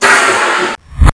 1 channel
Chute.mp3